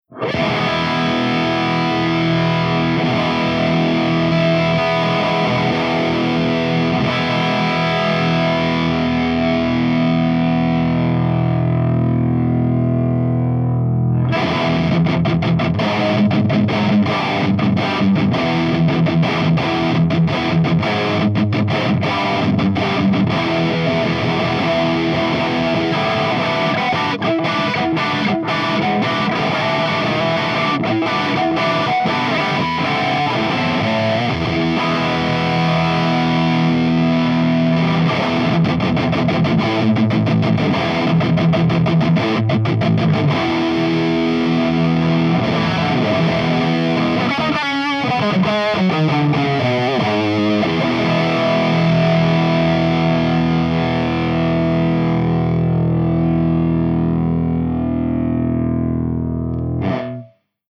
151_MESA SINGLE RECTIFIER_CH2HIGHGAIN_V30_HB